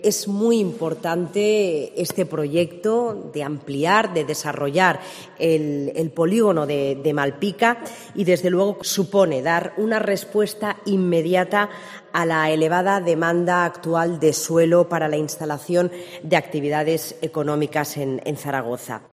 La ministra de Fomento, Raquel Sánchez, valora la operación industrial relacionada con el Polígono de Malpica.